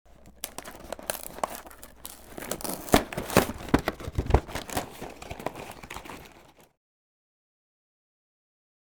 Cereal Box Opening Sound
household